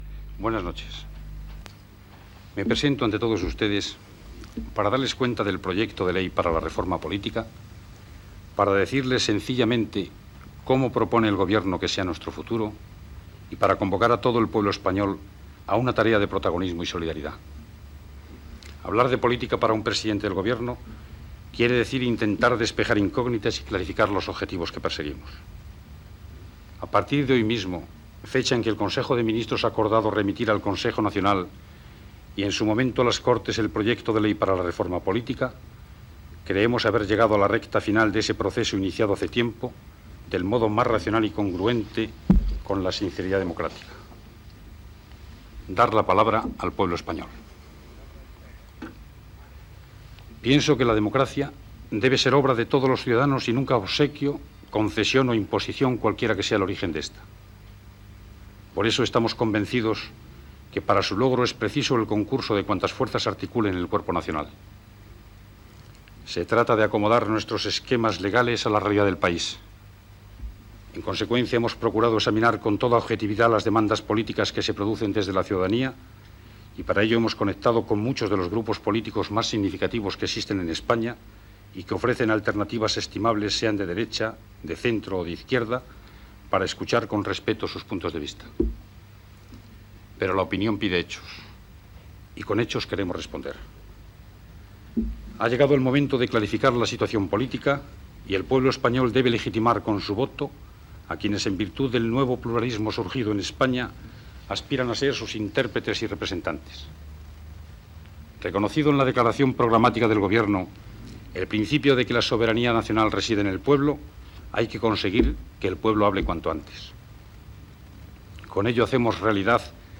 El president del govern Adolfo Suárez anuncia el projecte de Llei per a la Reforma Política.
Informatiu